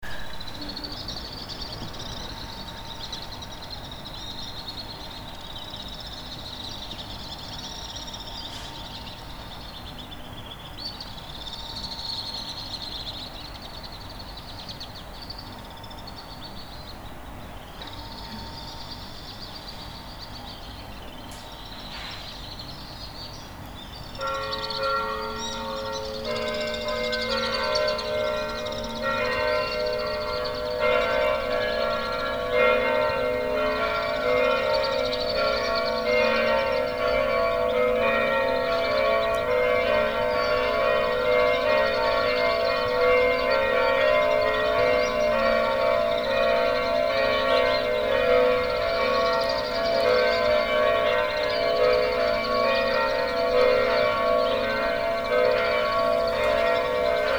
Data resource Xeno-canto - Soundscapes from around the world